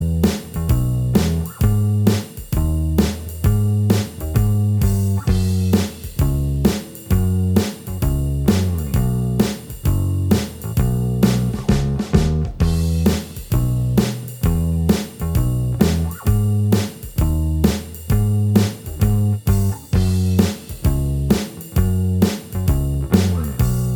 Minus All Guitars Indie / Alternative 3:32 Buy £1.50